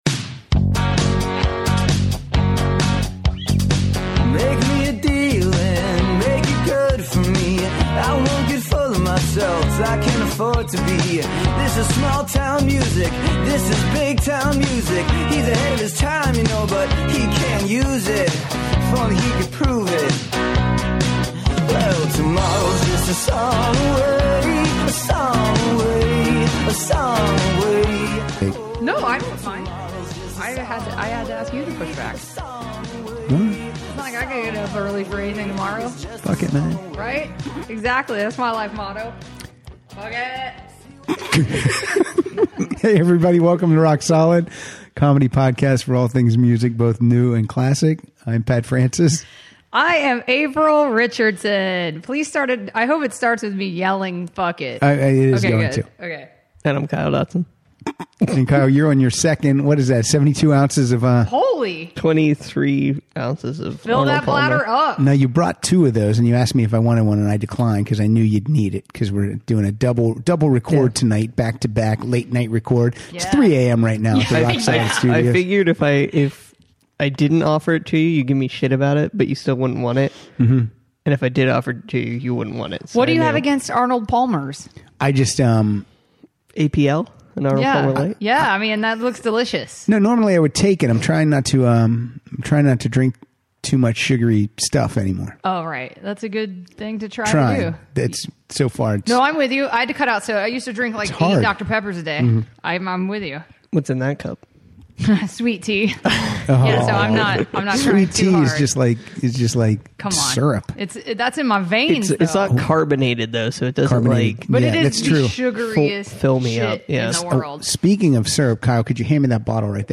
playing songs that have animals in the title.